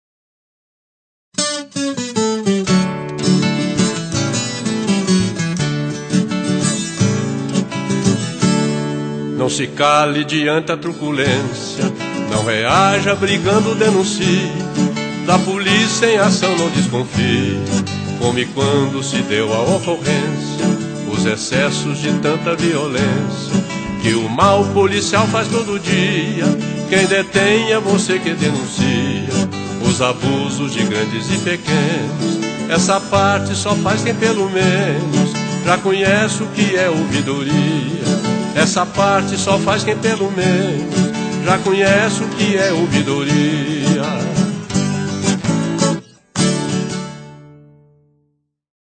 Faixa 9 - Spot Raiz 1